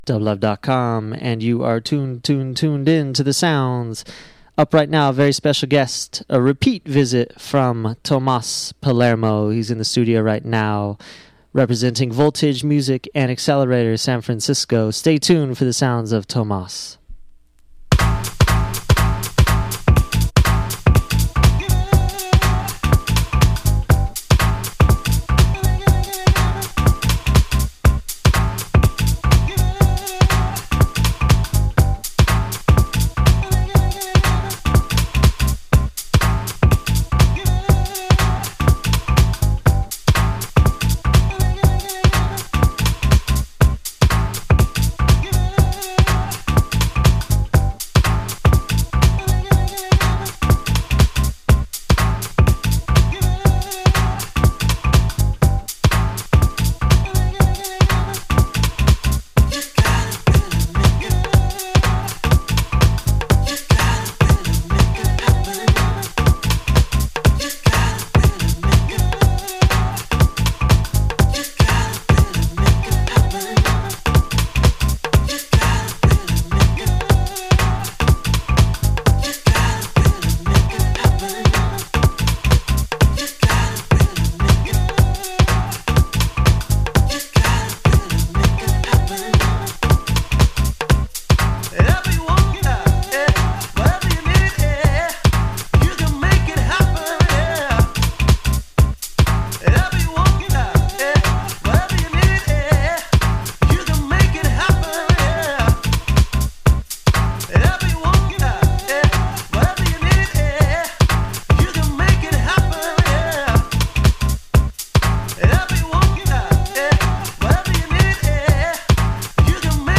Beats Dance Disco/House Electronic Hip Hop Rap